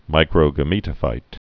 (mīkrō-gə-mētə-fīt)